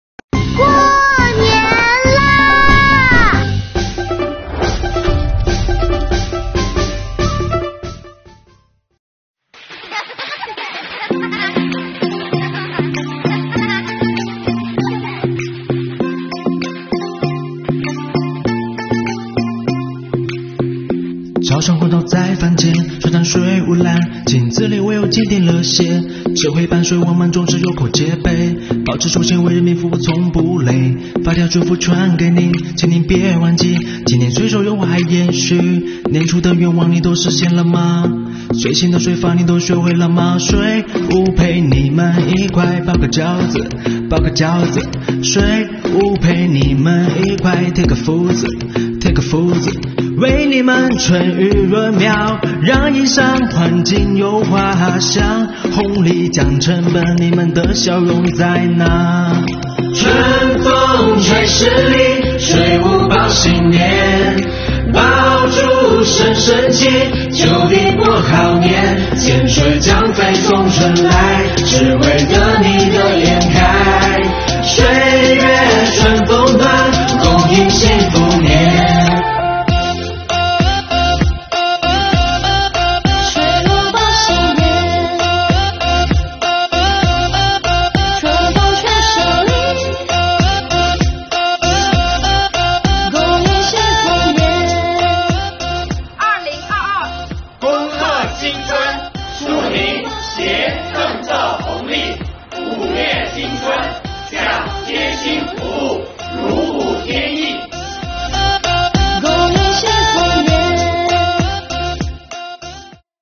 值此新春佳节之际广西各地税务干部用短视频向您送来最诚挚的新春祝福。